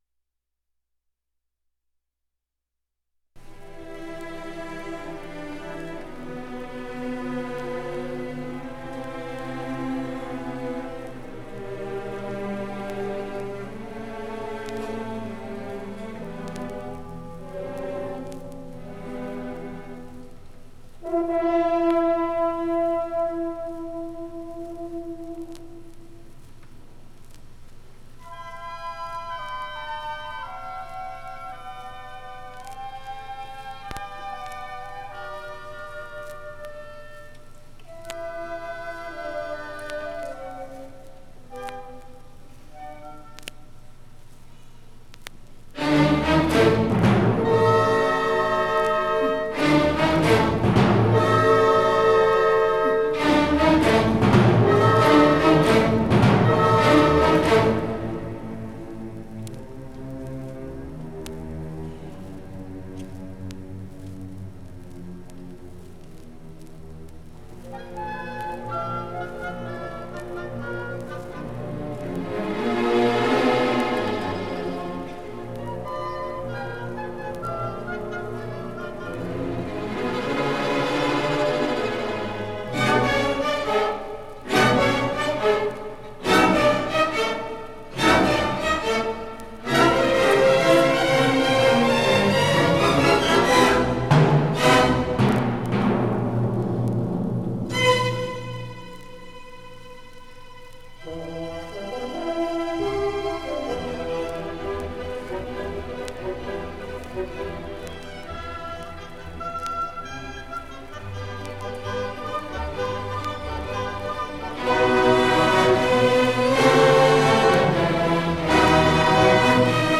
1976 Music in May band and orchestra performance recording · Digital Exhibits · heritage
It brings outstanding high school music students together on the university campus for several days of lessons and events, culminating in the final concert that this recording preserves.